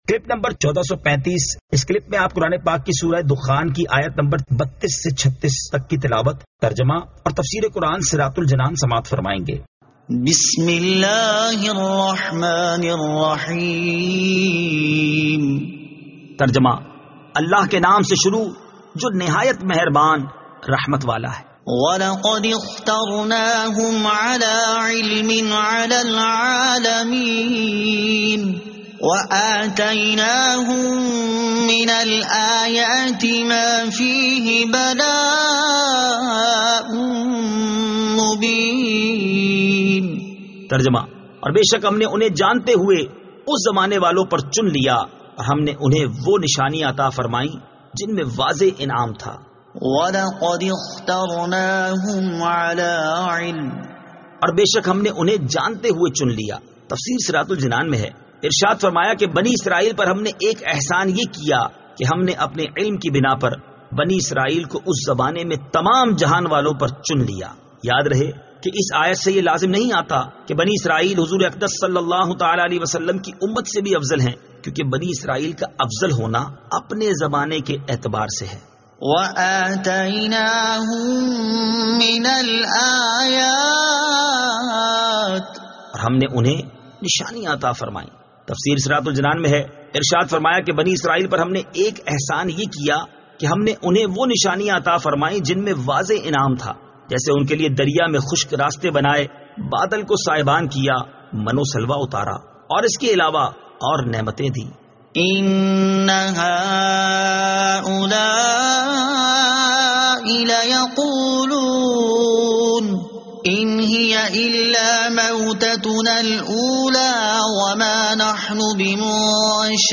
Surah Ad-Dukhan 32 To 36 Tilawat , Tarjama , Tafseer